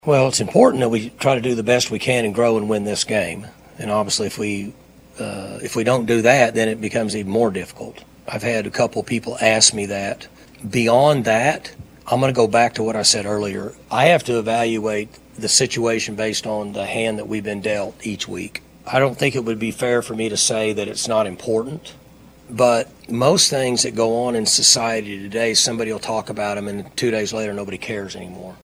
Oklahoma State head coach Mike Gundy met with the media on Monday afternoon in Stillwater.